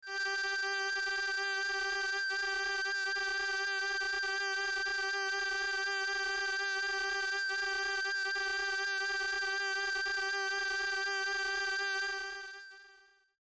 Akai AX80 Broken Old Strings " Akai AX80 Broken Old Strings F4（67 I711
标签： FSharp4 MIDI音符-67 赤-AX80 合成器 单票据 多重采样
声道立体声